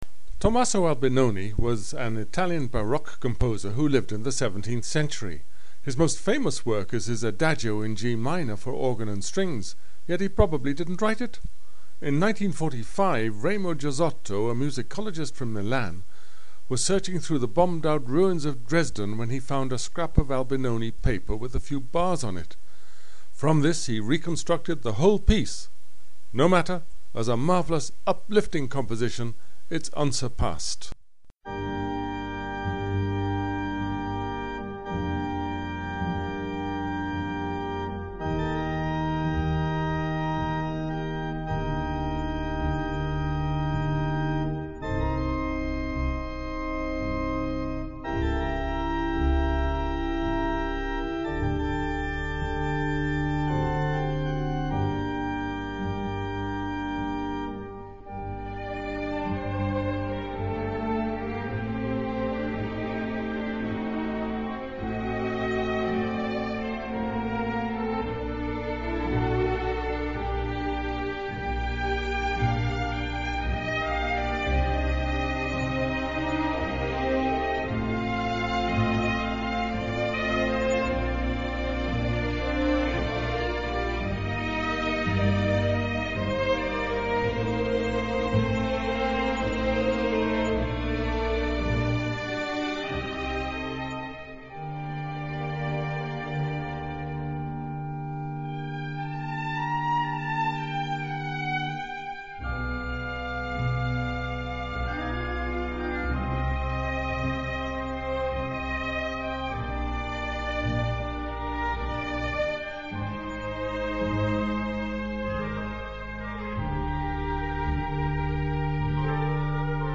Belcanto, Budapest - A Restaurant Review
But its prime attraction are wonderful singers - two beautiful young sopranos and a barrel-chested baritone, and the small orchestra of four that accompanies them, as indeed so do all the waiters and waitresses from time to time.
I was entranced at how they played my own request, Albinoni's haunting Adagio in G-minor for strings and organ, which you can hear